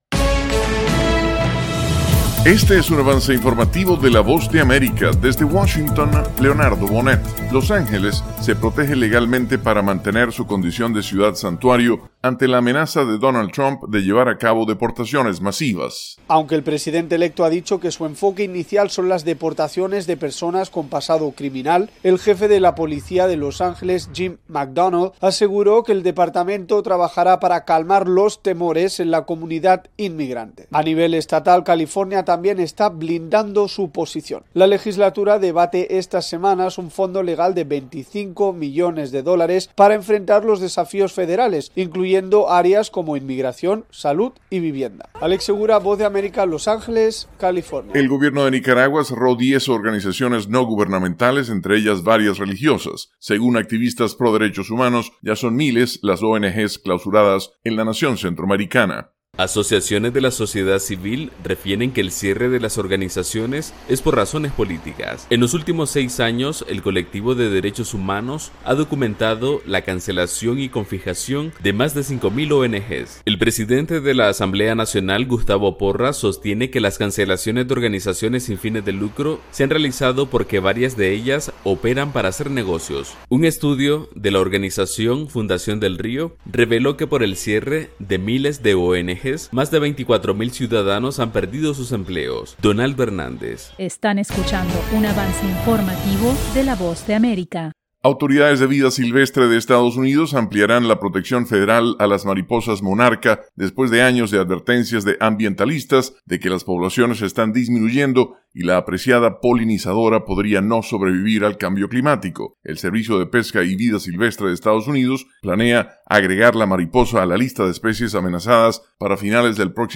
El siguiente es un avance informativo presentado por la Voz de América, desde Washington, con